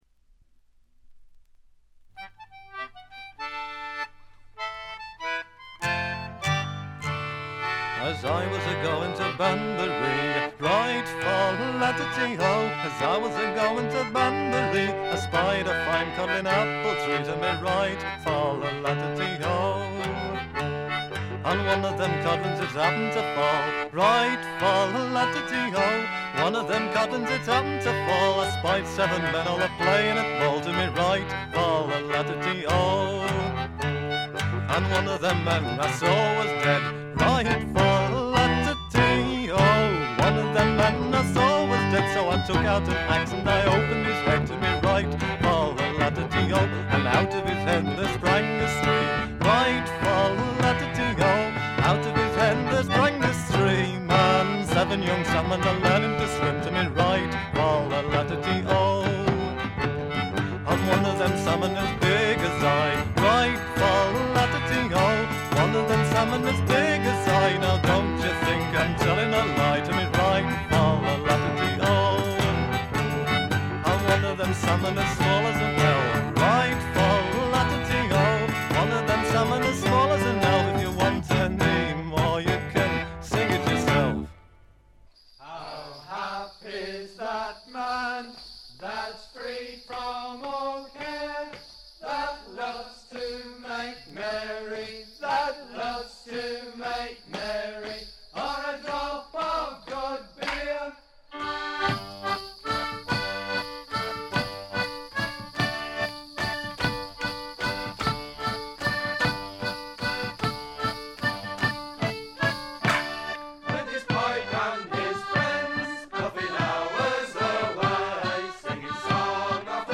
部分試聴ですが、わずかなノイズ感のみ、良好に鑑賞できると思います。
1st同様に豪華メンバーによる素晴らしいエレクトリック・トラッドです。
試聴曲は現品からの取り込み音源です。